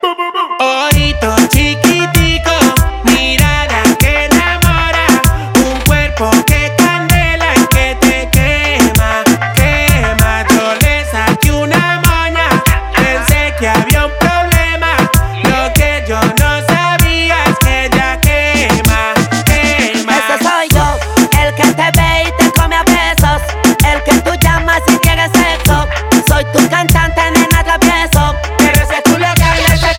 un perreo de la vieja escuela